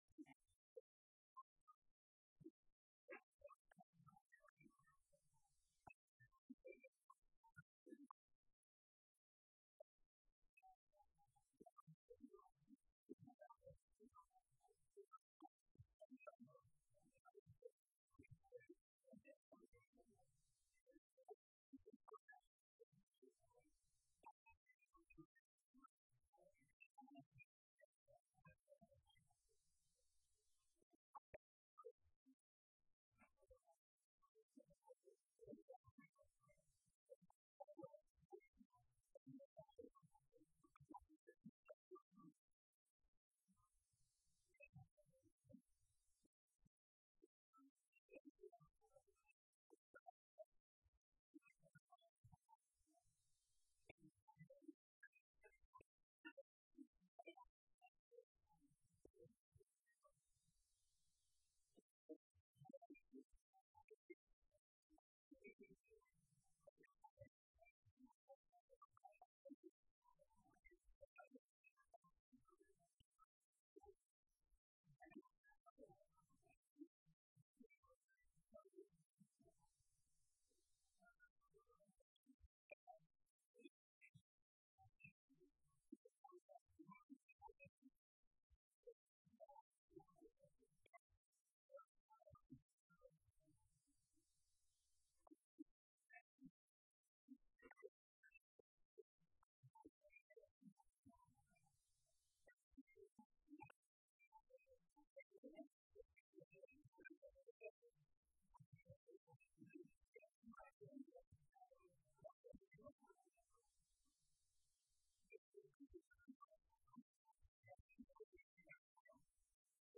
El pasado domingo 28 de Octubre de 2007 se celebro un año más el que es ya el décimo quinto encuentro - convivencia entre las tres Hermandades de la Parroquia de Santiago El Mayor.
charla